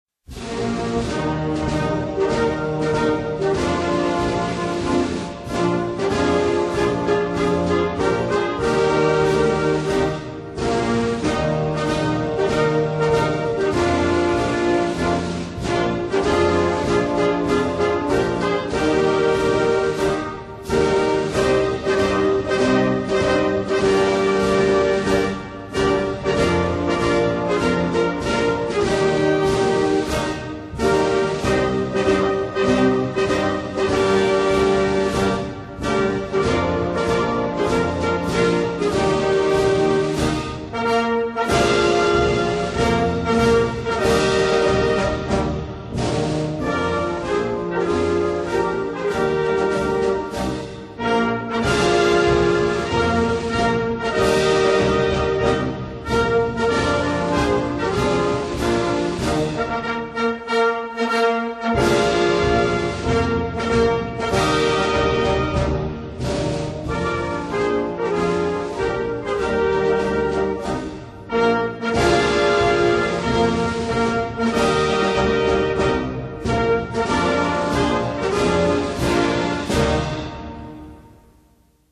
（2）国歌